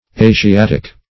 Asiatic \A`si*at"ic\, a. [L. Asiaticus, Gr.